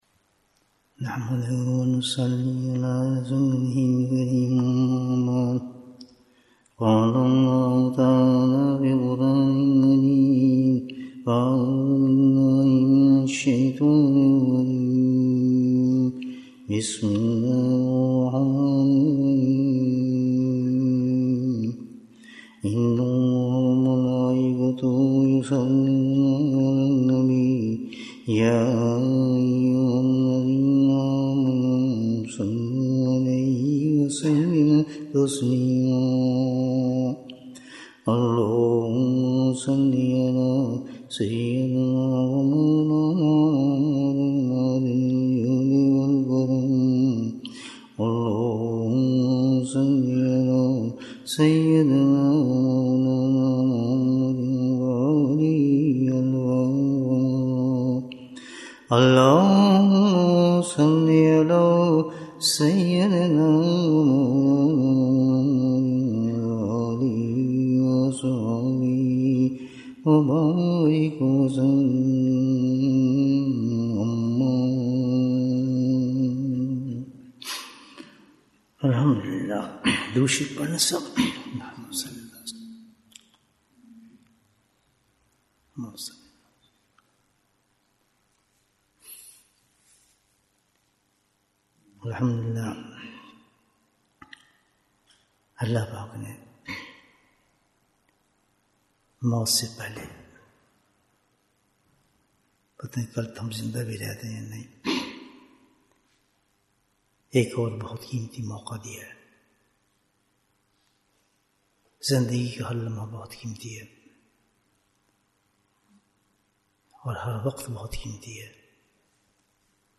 Bayan, 44 minutes29th August, 2024